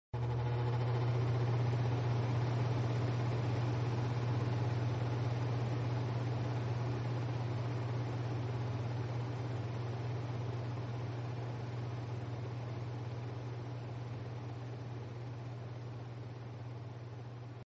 На этой странице собраны различные звуки работающего вентилятора: от тихого гула компьютерного кулера до мощного потока воздуха из бытового прибора.
Звук работающего вентилятора